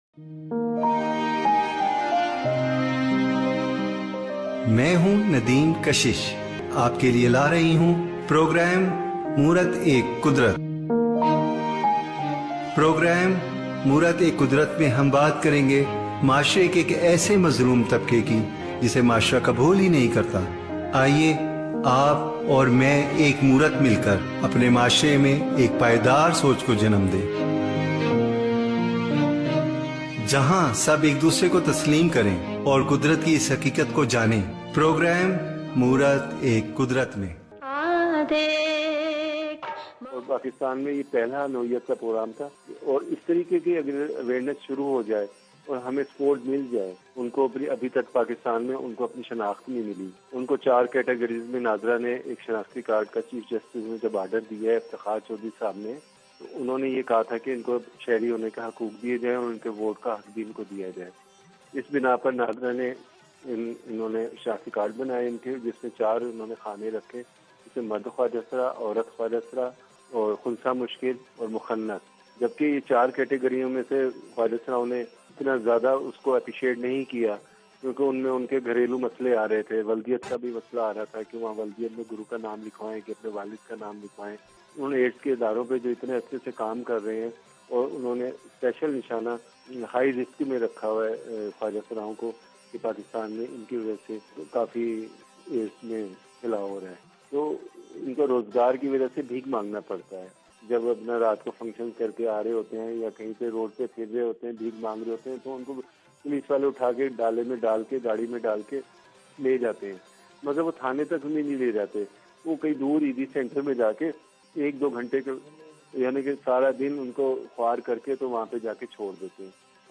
بات چیت